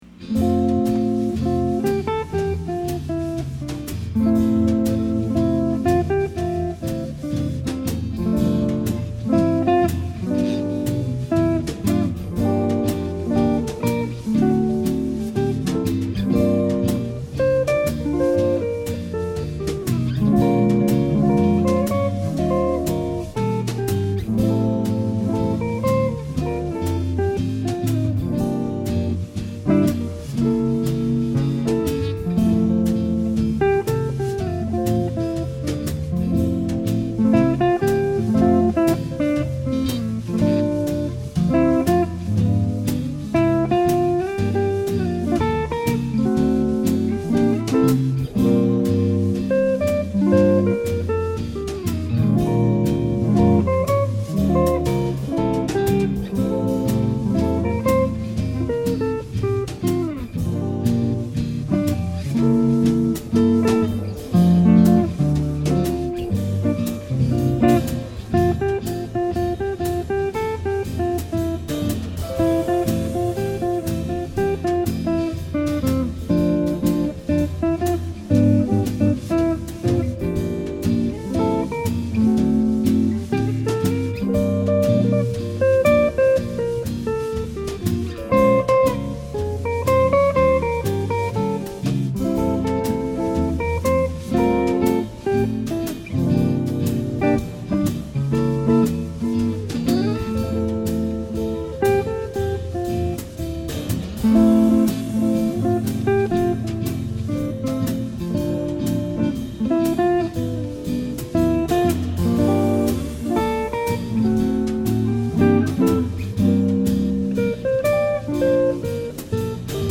Guitar Works